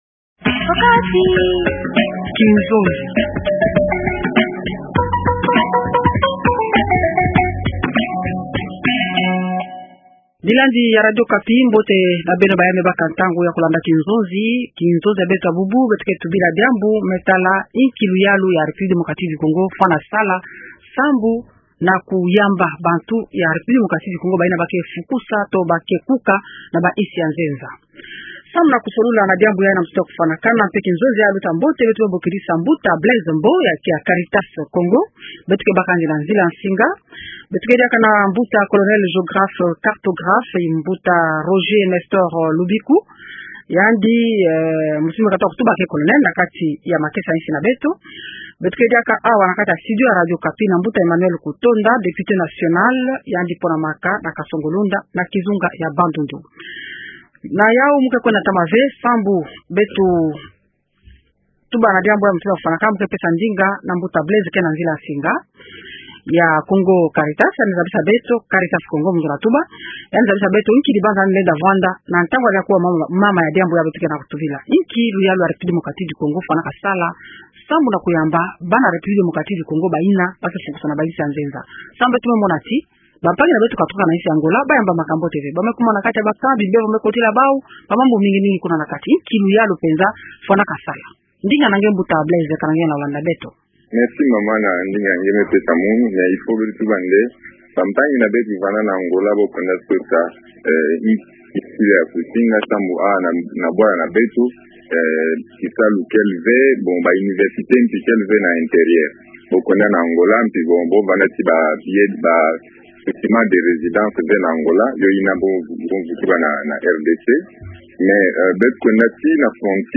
Invités :rn-Emmanuel KUTONDA député national